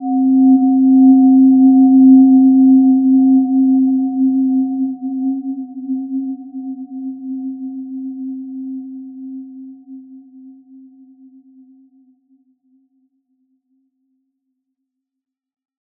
Gentle-Metallic-4-C4-p.wav